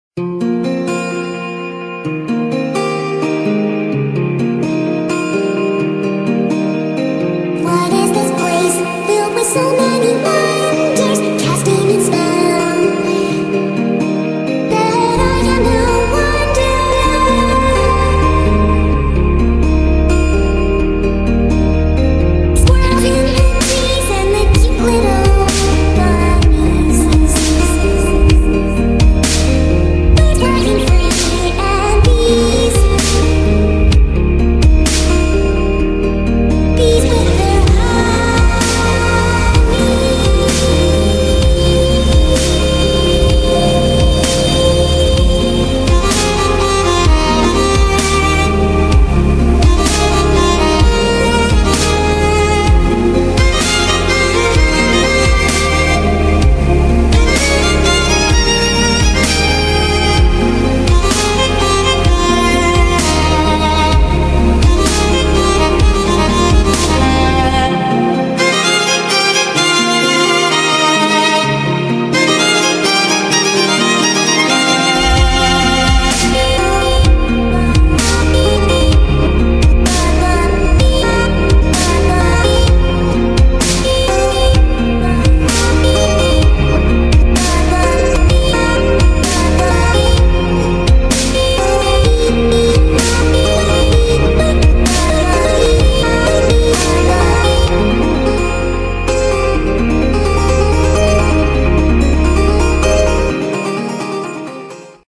BPM64
Audio QualityPerfect (High Quality)
It doesn't fade out until that very last note.